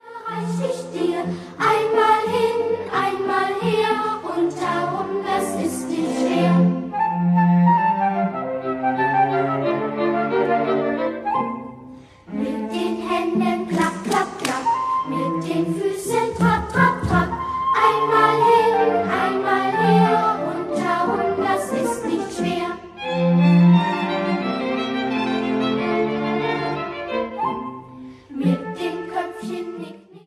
Forme couplet-refrain associant des gestes.